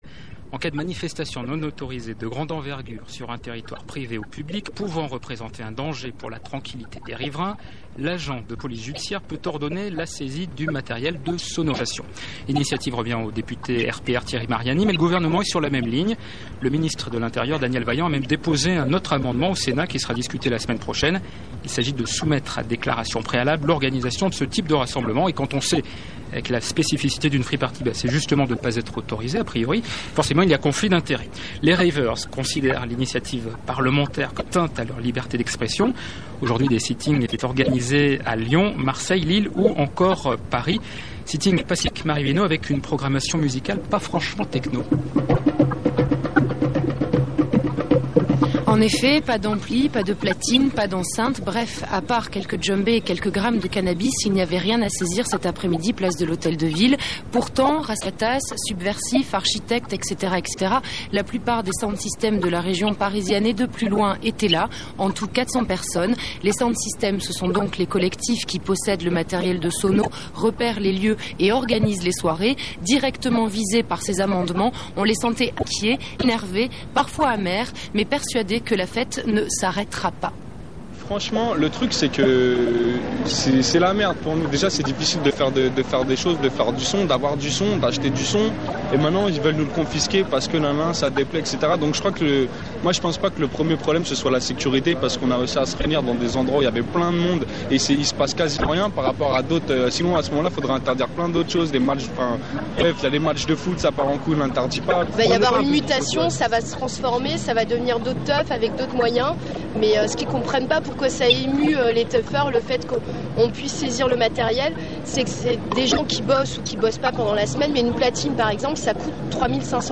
Sample interview mp3 de France culture